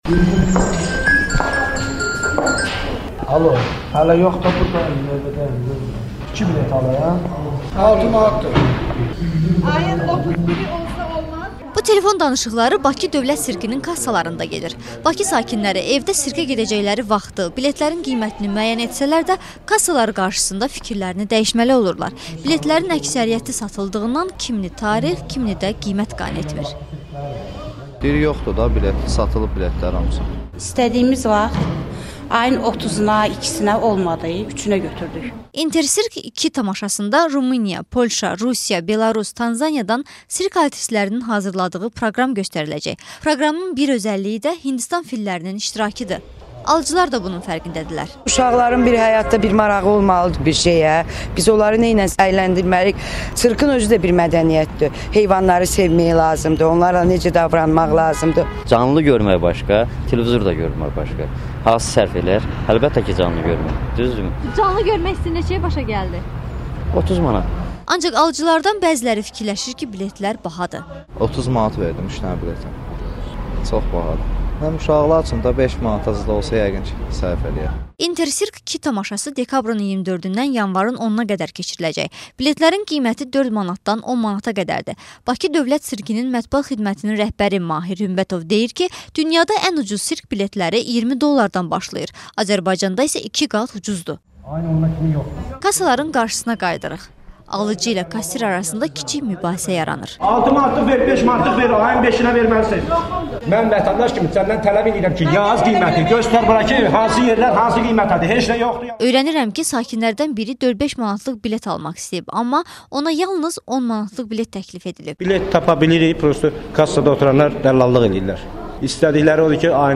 Bakı Dövlət Sirkinin kassalarının qarşısında adamların narahat telefon danışıqları eşidilir. Alıcı ilə kassir arasında kiçik mübahisə yaranır.